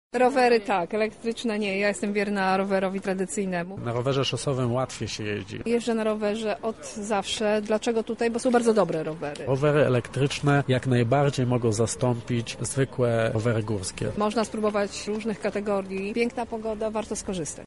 O obu typach rowerów mówią ci, którzy przetestowali pojazdy: